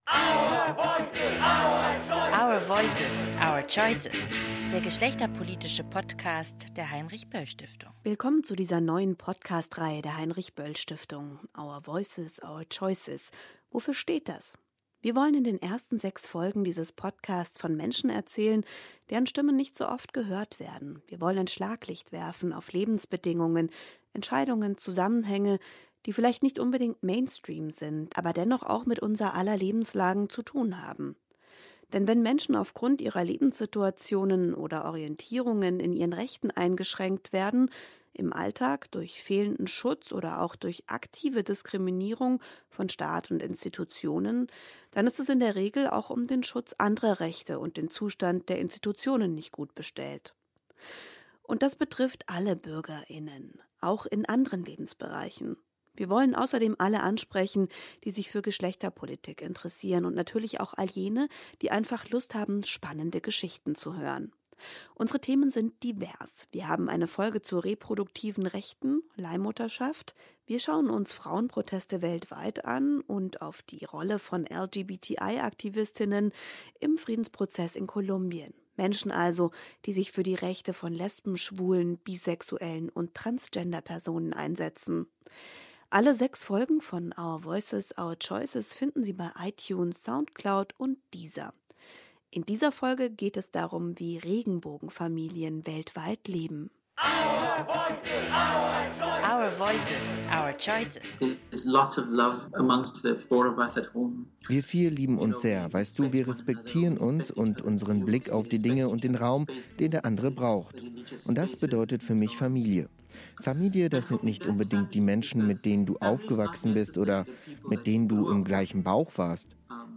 In diesem Podcast erzählen drei ganz unterschiedliche Personen davon, wie es ihnen dabei ergangen ist, Familien zu gründen, Rechte zu erkämpfen und legale Unsicherheiten auszuhalten.